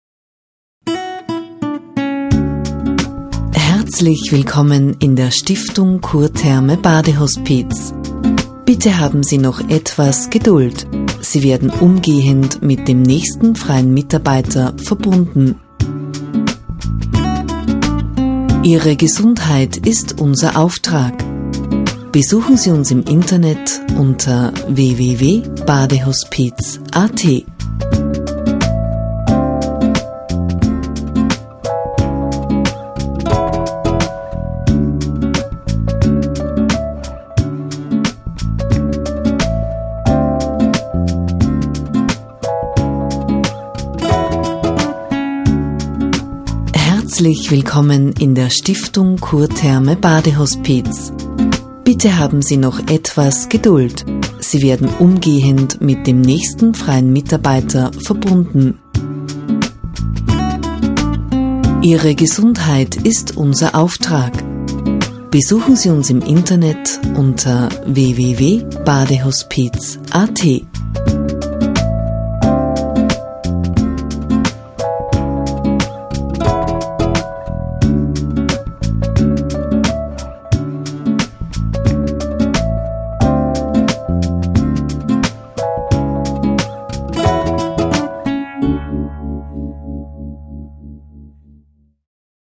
Die Telefonansage sollte Entspannung natürlich bereits über's Telefon transportieren.